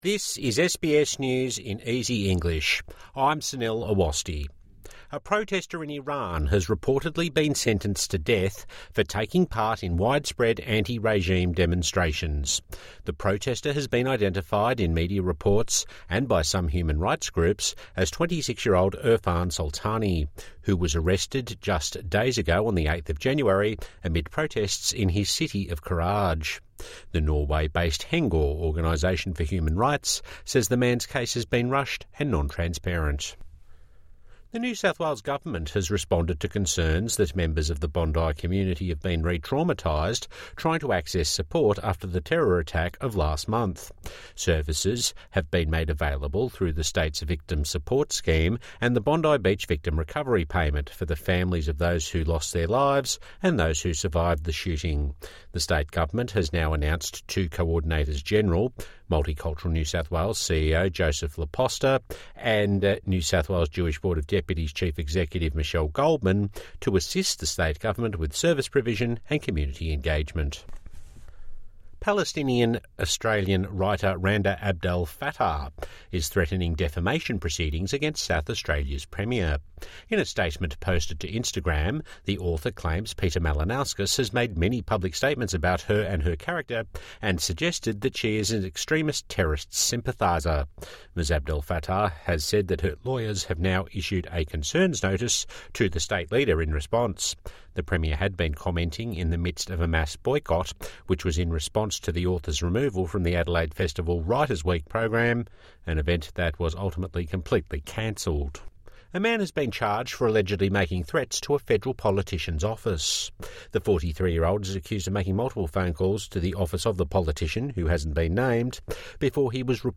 A daily news bulletin for English learners and people with a disability.